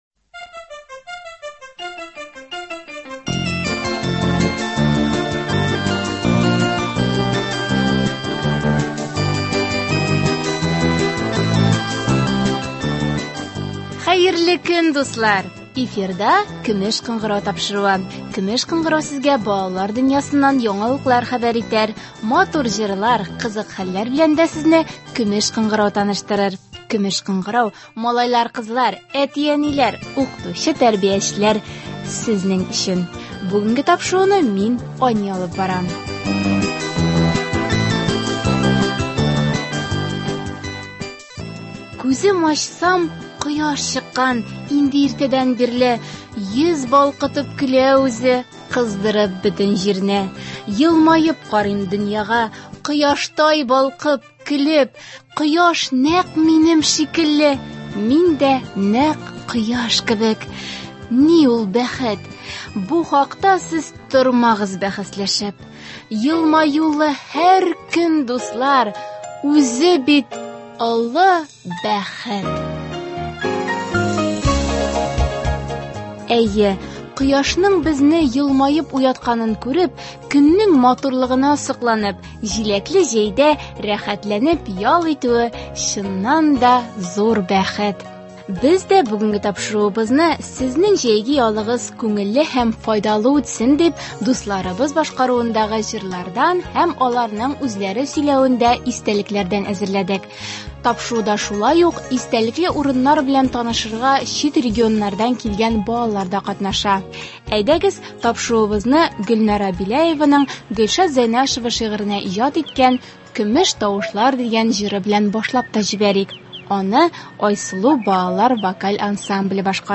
Без бүгенге тапшыруыбызда дусларыбызның җәйге ялы турындагы күңелле һәм файдалы истәлекләрен тыңларбыз һәм әлеге хатирәләр матур җырлар белән үрелеп барыр.